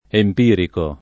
empirico [ emp & riko ]